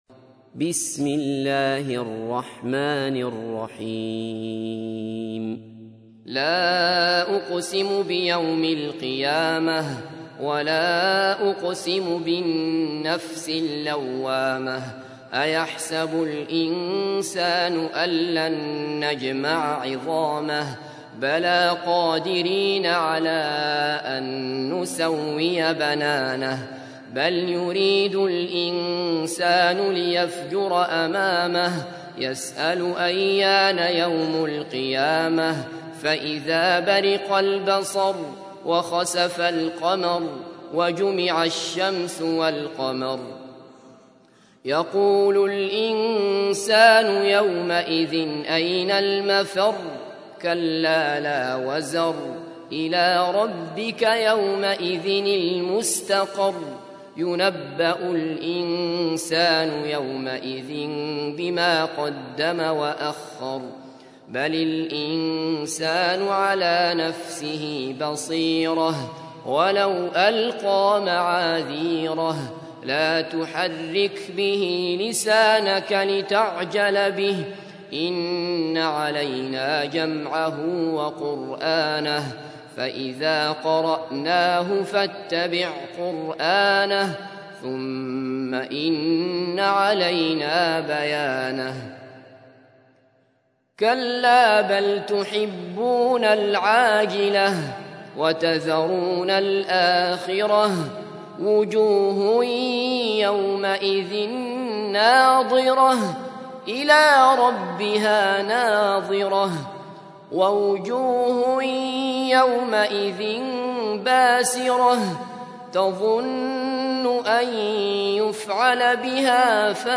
تحميل : 75. سورة القيامة / القارئ عبد الله بصفر / القرآن الكريم / موقع يا حسين